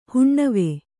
♪ huṇṇave